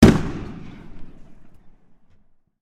Звуки взрывов разные
Одиночный хлопок взрыва
odinochnyi-khlopok-vzryva.mp3